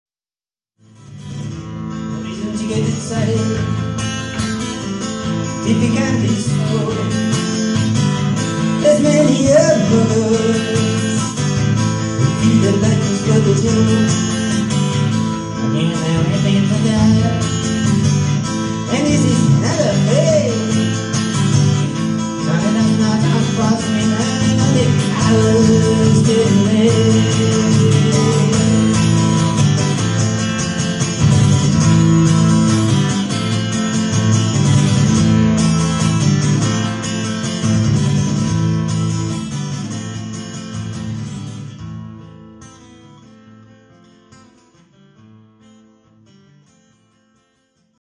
Malga Ime (Verona - Italy)